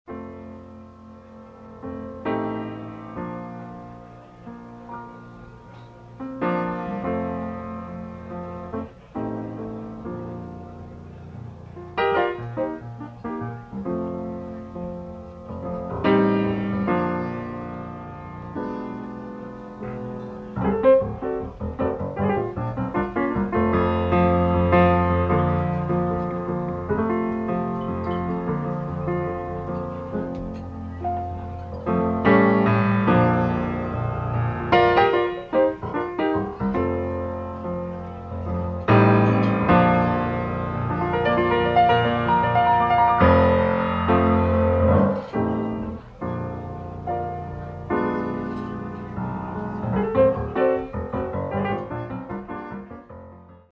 Acoustic and electric pianos
Tenor and soprano saxophones
Acoustic bass
Drums
Recorded live at George's, Toronto, October 1975